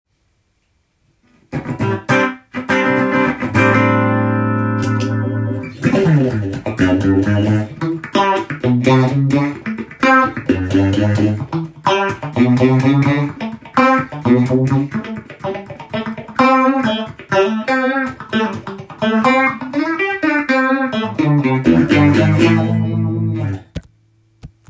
このエフェクターも変わった音がするので
試しに弾いてみました
コーラスのようなワウのような空間系の面白い音がします。